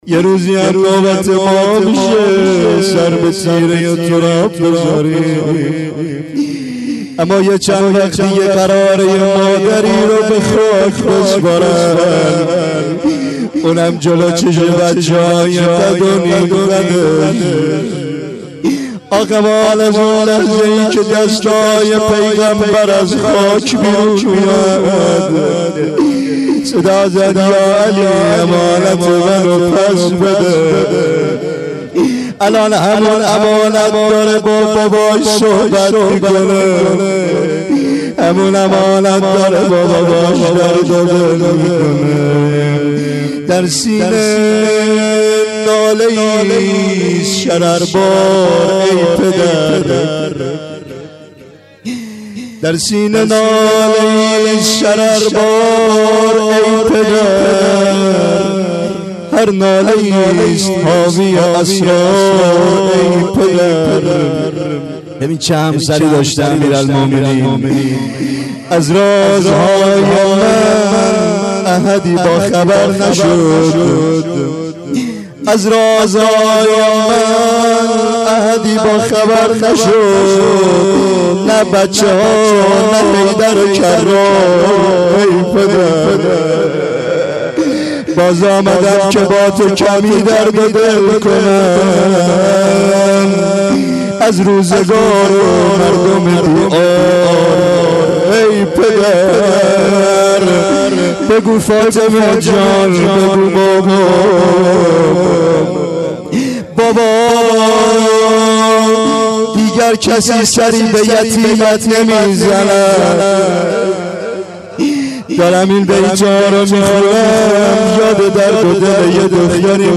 روضه.در-سینه-ناله-ایست-شرربار-ای-پدر.mp3